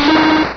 Cri de Scorplane dans Pokémon Rubis et Saphir.